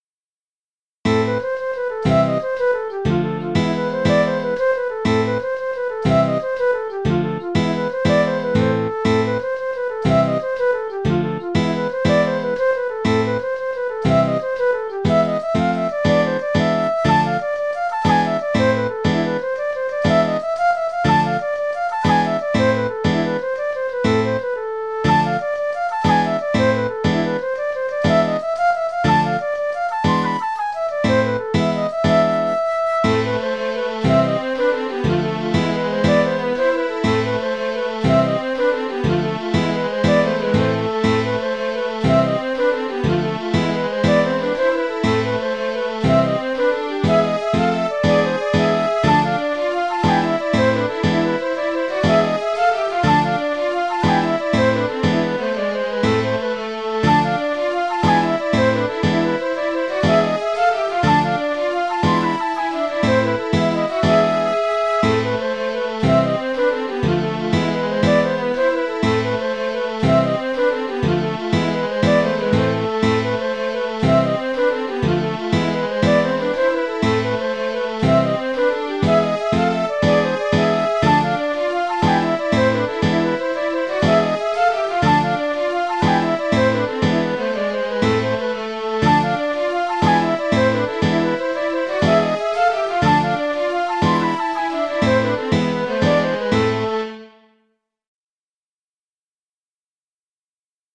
J’ai composé cette jig un peu comme une fantaisie, pour expérimenter l’usage du ré# dans une gamme de La m. Que l’on m’excuse de ne pas en avoir fait de tablature, mais mes amis du diato ne me l’ont pas réclamée.
Jig du 22 mars